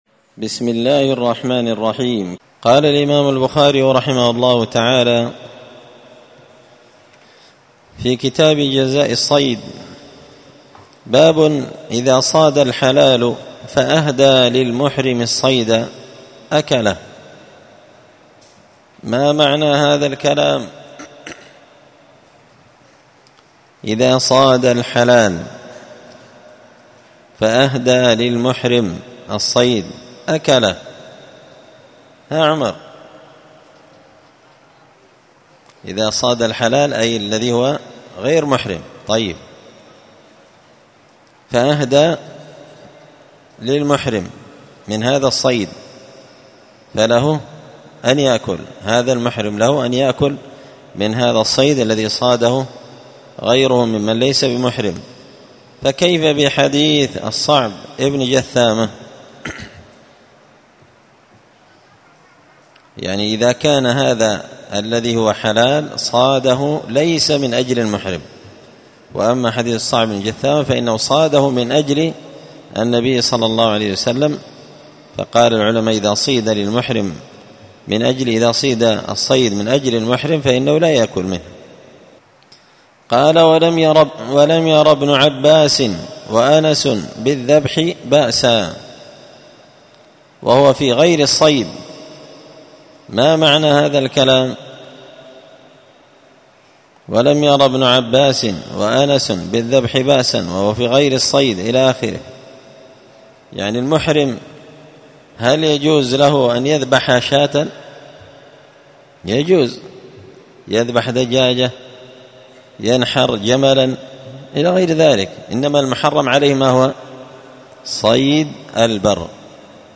كتاب جزاء الصيد من شرح صحيح البخاري- الدرس 4 باب إذا صاد الحلال فأهدى للمحرم الصيد أكله
مسجد الفرقان قشن المهرة اليمن